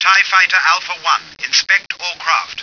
The effect I need I’ll add an example of in a bit, but it sounds like a radio transmission.
I can add white noise in the back ground, but in the game, the noise isn’t constant - it is like an echo.
It sounds like quantization noise …